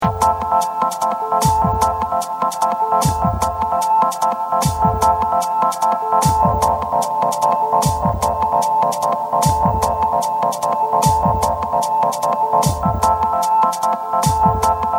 タイトルをつけるのに苦労しましたが、なんとなく聴いてるうちにサイレンを連想したのでこのタイトルに。